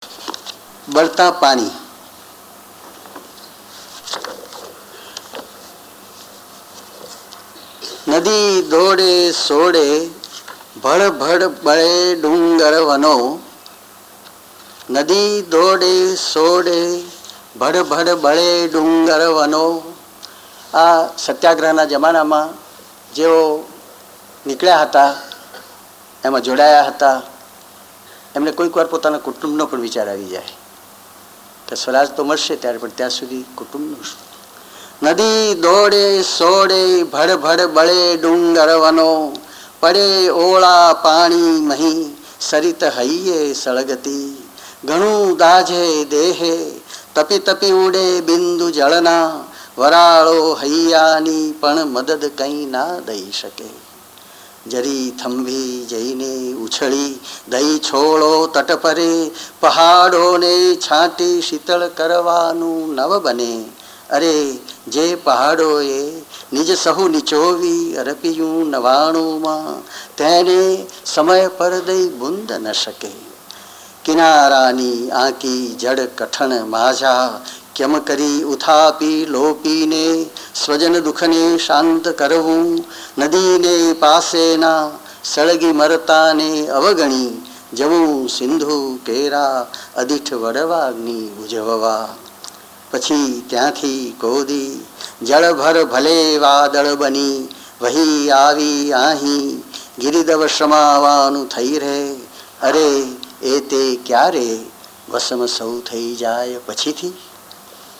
બળતાં પાણી • કવિના સ્વમુખે કાવ્યપઠન